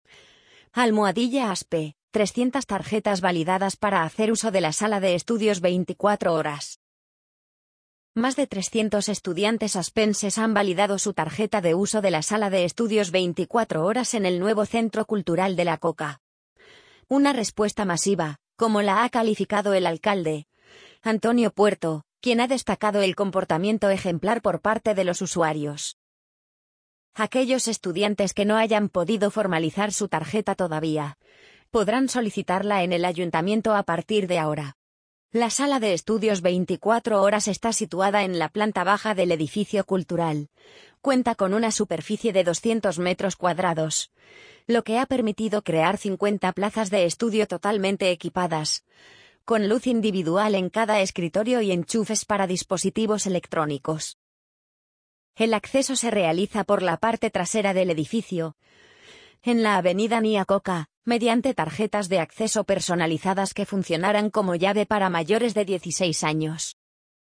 amazon_polly_60329.mp3